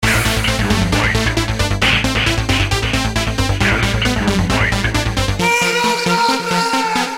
Ten sam oryginalny fragment utworu został zpróbkowany z komputera PC samplerem produkcji BIW.
16 bit 44,1kHz]